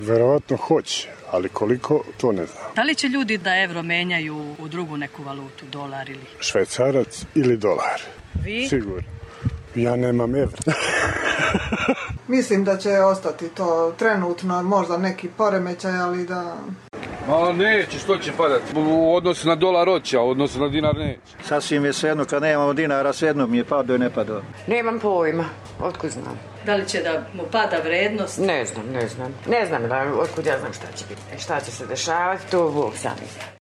Užičani o evru